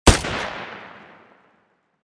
PistolShot02.wav